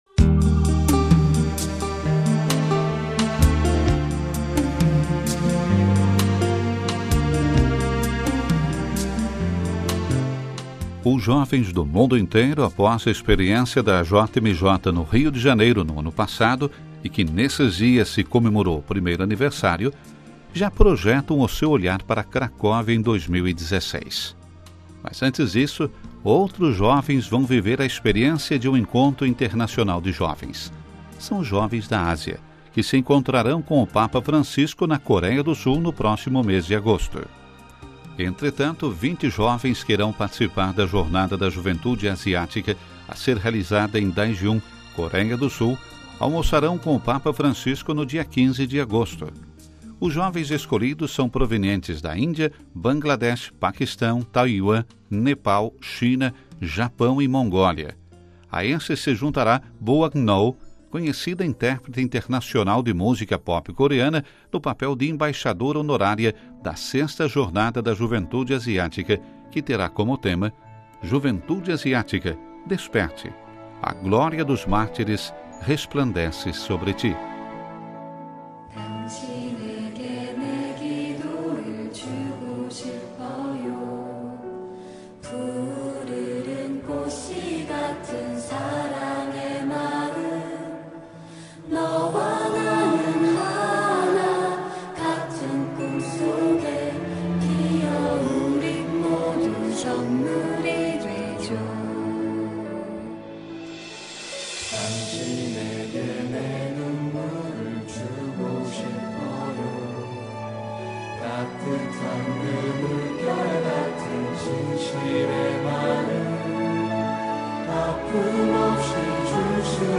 Nestes dias momentos de recordação do primeiro aniversário da JMJ no Rio de Janeiro, nós conversamos com o Arcebispo do Rio, Cardeal Orani João Tempesta.